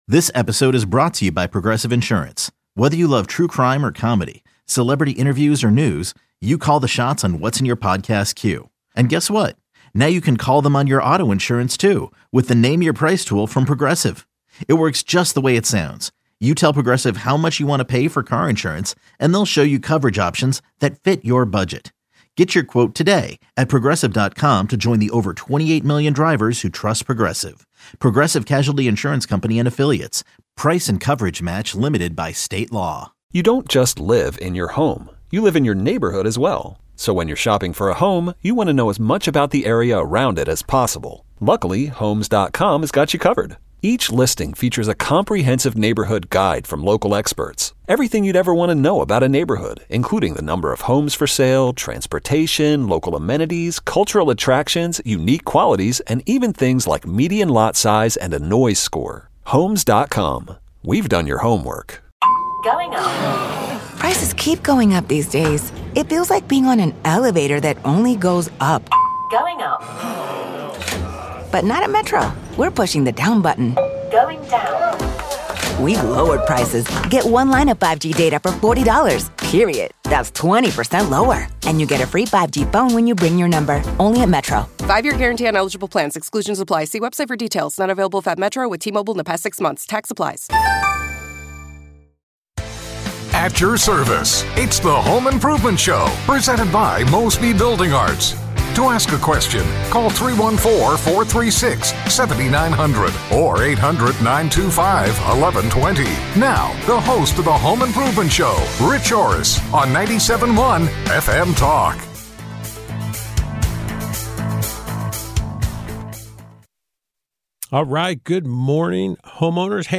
From fixing common household issues to exploring the latest DIY trends, get valuable insights to enhance your home. Call in with your questions and join the conversation on making your home the best it can be!